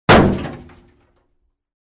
Удар кулаком по кузову машины